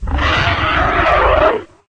flesh_panic_1.ogg